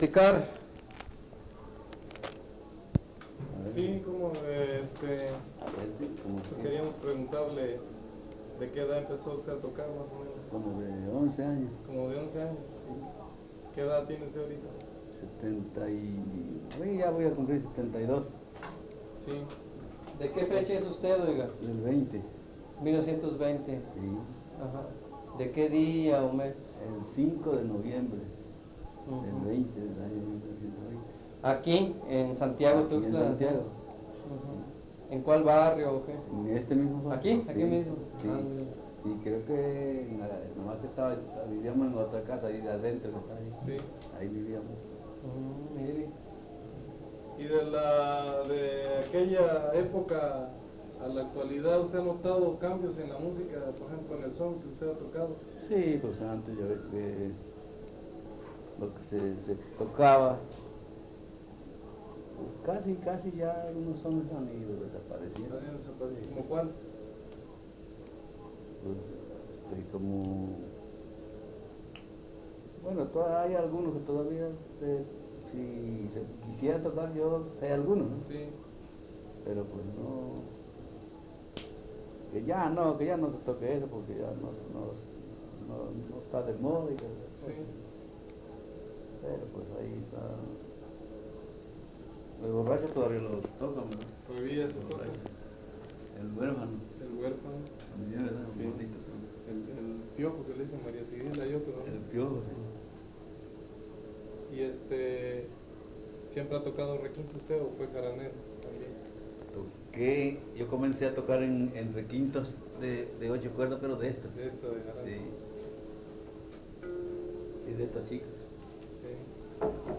Entrevista
Santiago Tuxtla, Veracruz, Mexico